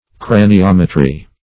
Craniometry \Cra`ni*om"e*try\ (kr?`n?-?m"?-tr?), n.